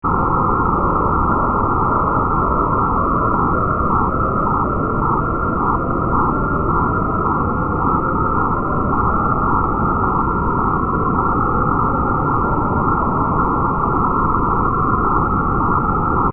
Demo 5: Scales
These major scales span the existence region of dichotic pitch. The scales start at C (65 Hz) and run through 4 octaves to C (1047 Hz). The notes include harmonics (this facilitates the dichotic pitch percept).
majorScale5.aif